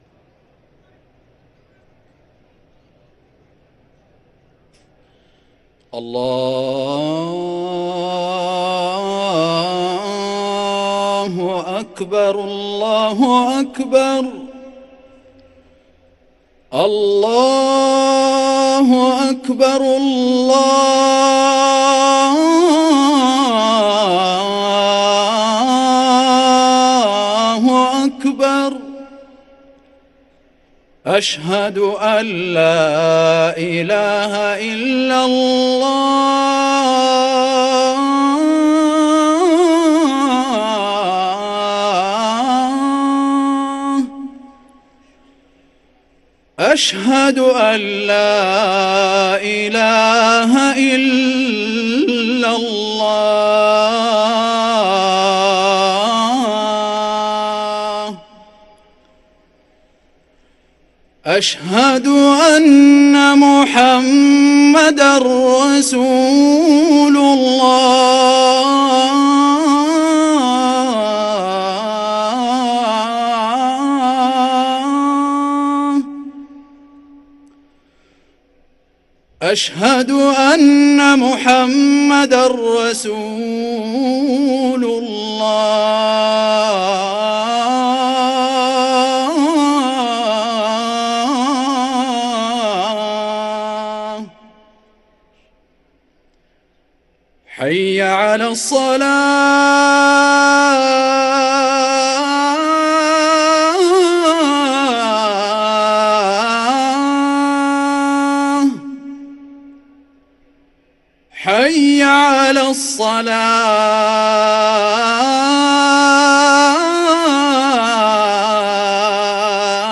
أذان العشاء